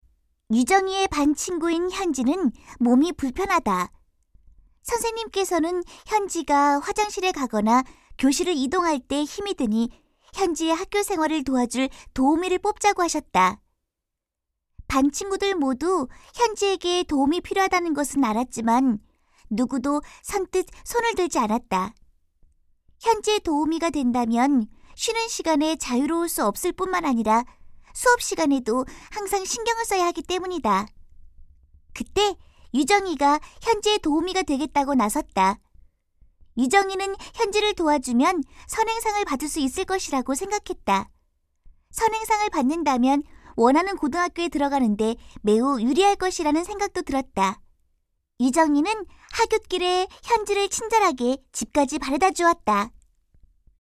069쪽-내레이션.mp3